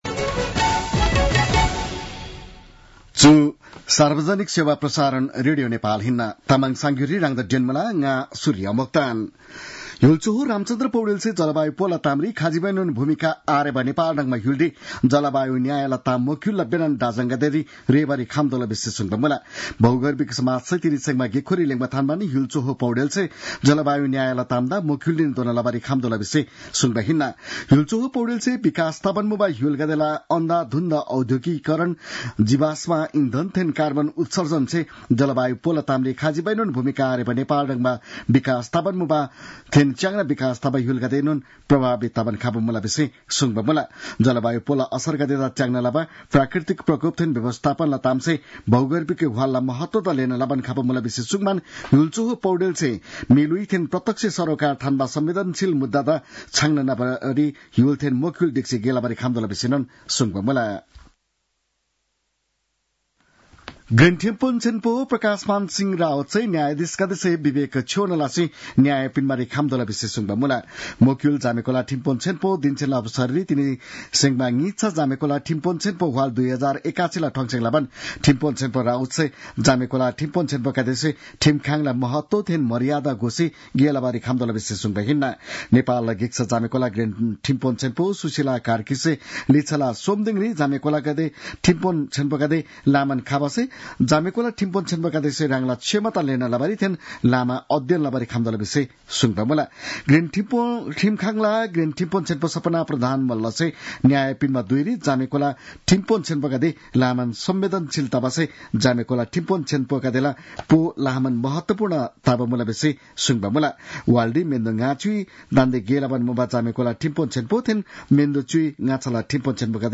तामाङ भाषाको समाचार : २७ फागुन , २०८१
Tamang-news-11-26.mp3